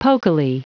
Prononciation du mot pokily en anglais (fichier audio)
Prononciation du mot : pokily